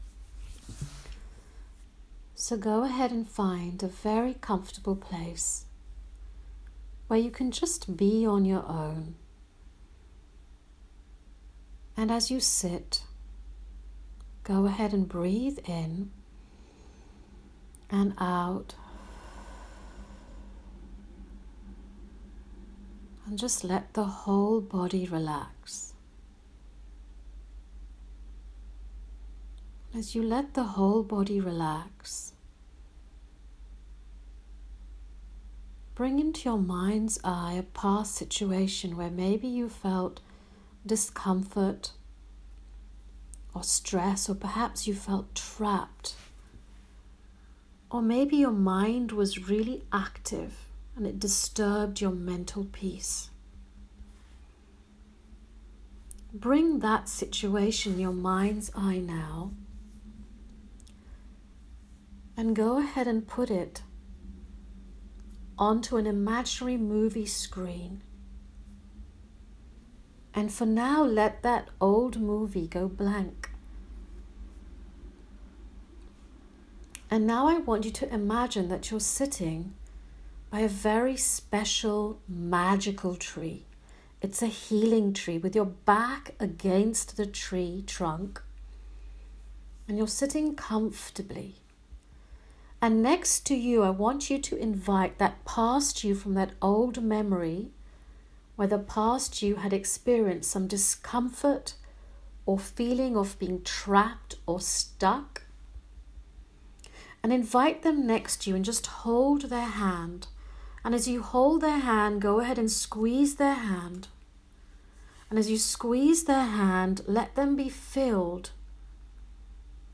a healing meditation